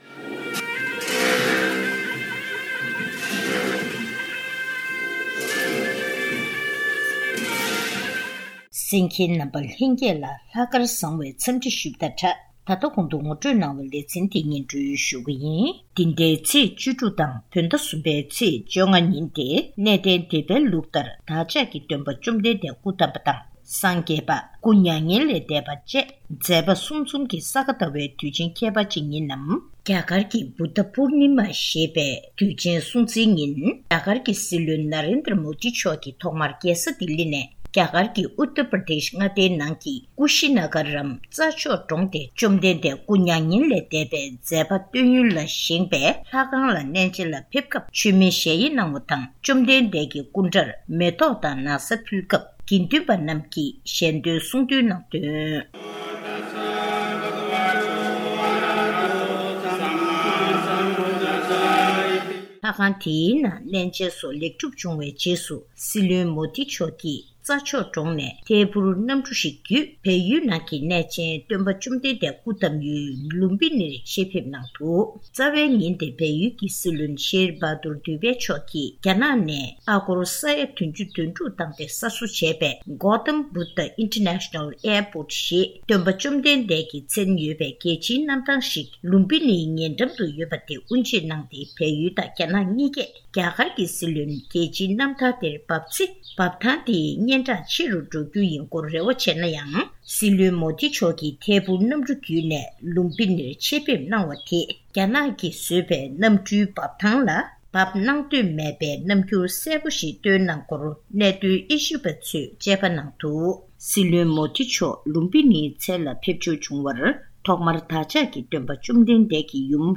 ཆོས་ཕྱོགས་དང་ཆབ་སྲིད་ཀྱི་གལ་གནད་སྐོར་འབྲེལ་ཡོད་ལ་བཀའ་འདྲི་ཞུས་པ།